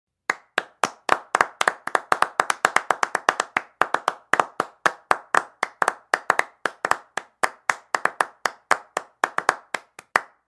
Clapping(2 person).ogg